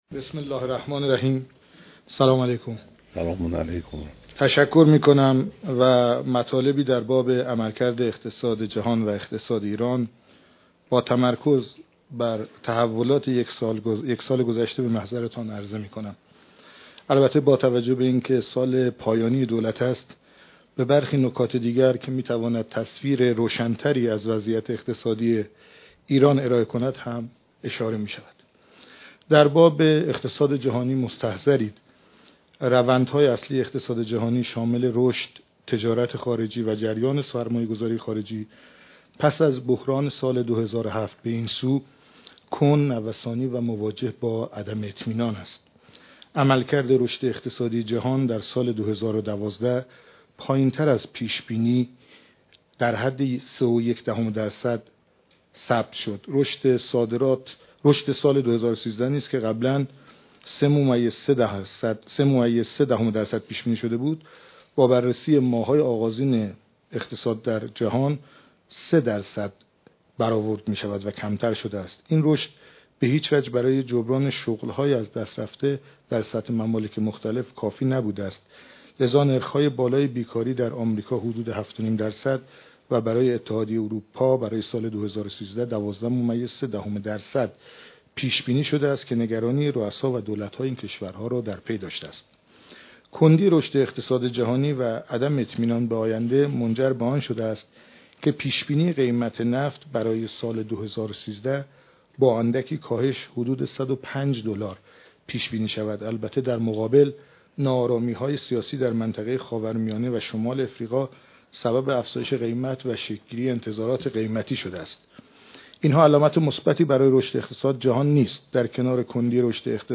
دیدار رئیس جمهور و اعضای هیئت دولت